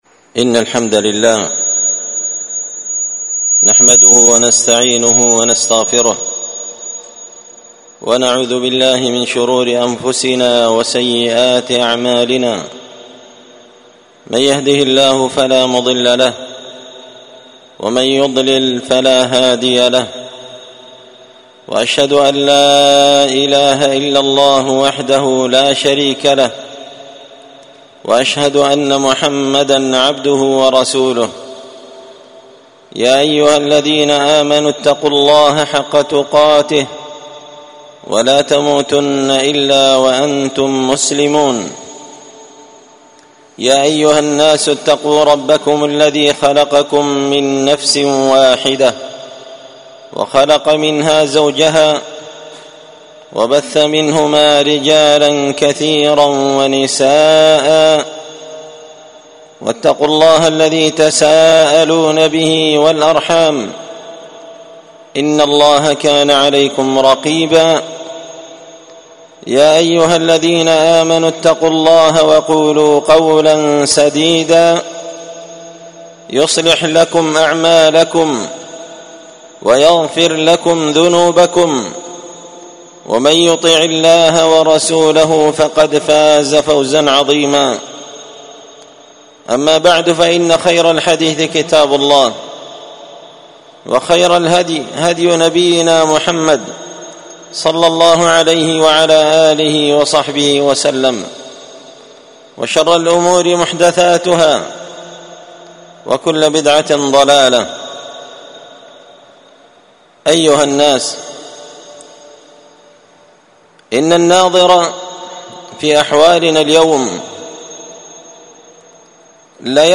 خطبة جمعة بعنوان: